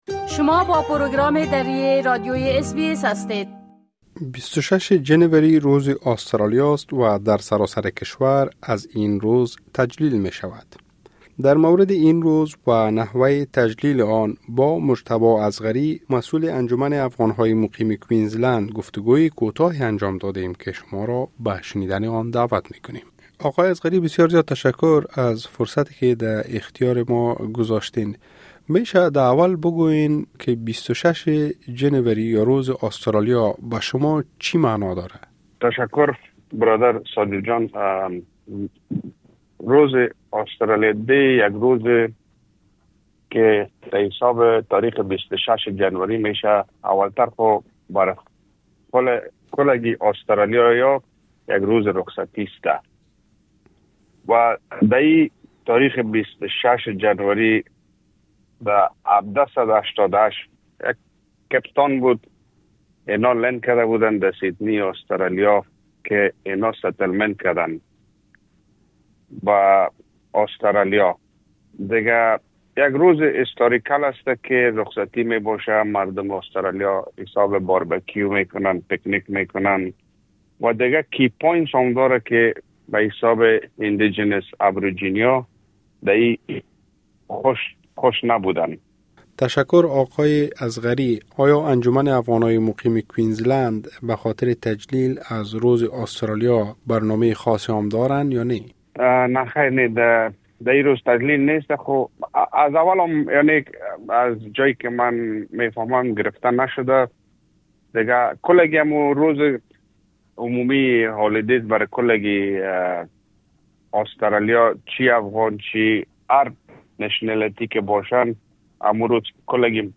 ۲۶ جنوری برای افغان‌های مقیم آسترالیا چه معنای دارد؟ گفتگو